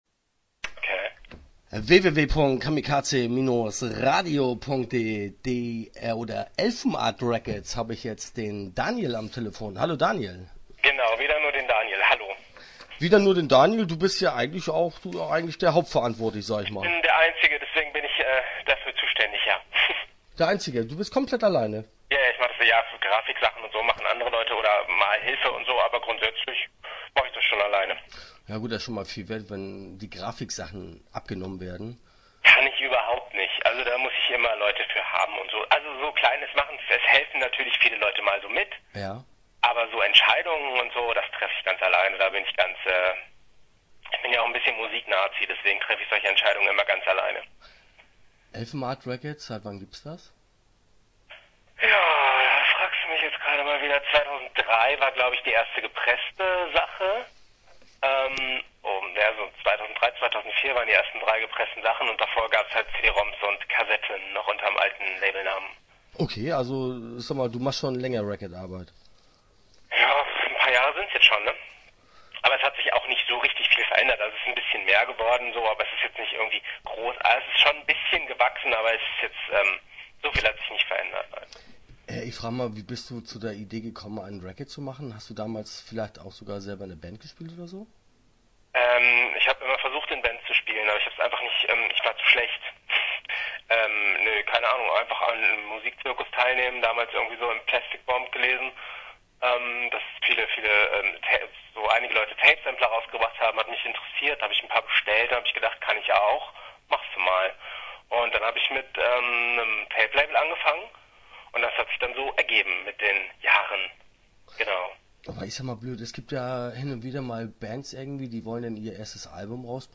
Interview Teil 1 (11:52)